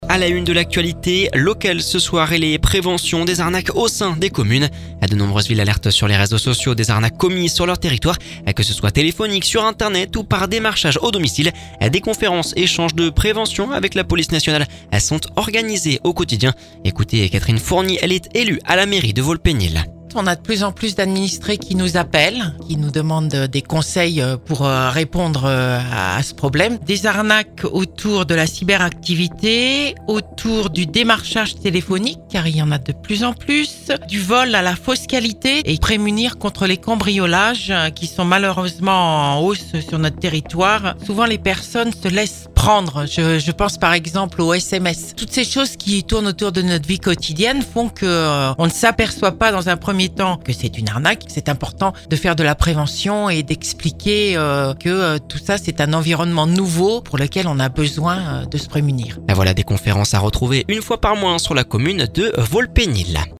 Catherine Fournier, élu à la mairie de Vaux-le-Pénil…